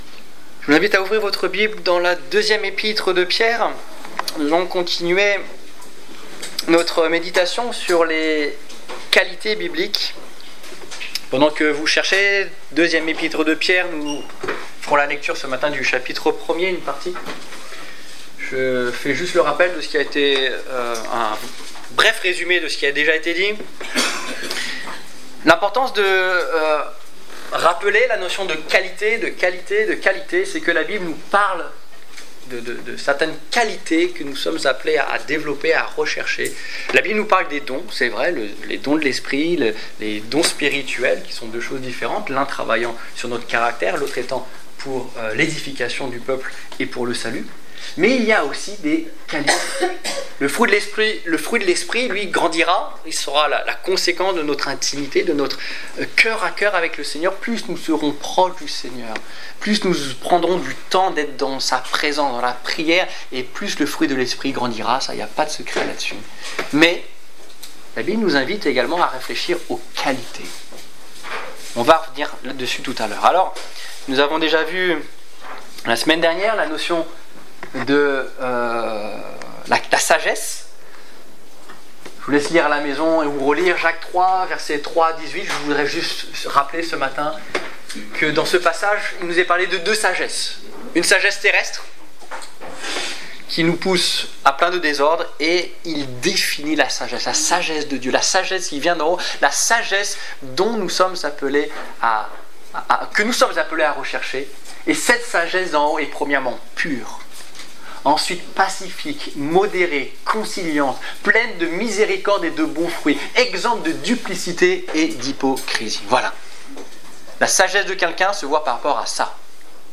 Quelques qualités bibliques - La vertu Détails Prédications - liste complète Culte du 20 septembre 2015 Ecoutez l'enregistrement de ce message à l'aide du lecteur